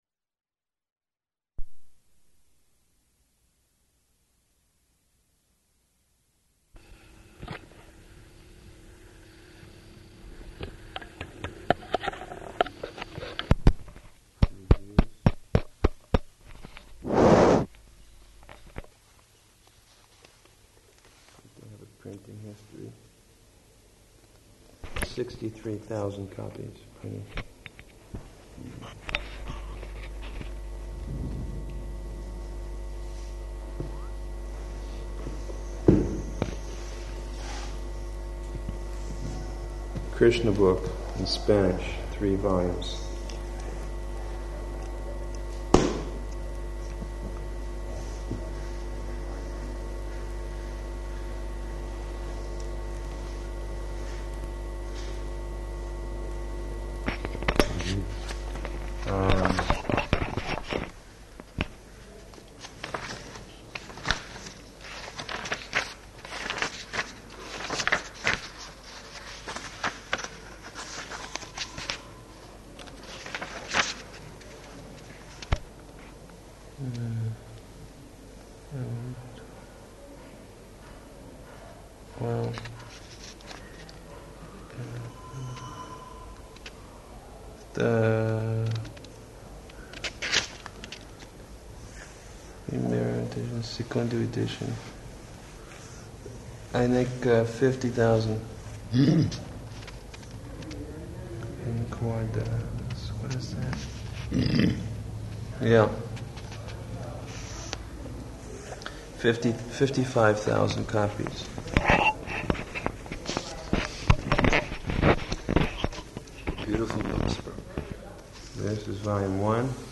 Room Conversation New Books
-- Type: Conversation Dated: November 2nd 1977 Location: Vṛndāvana Audio file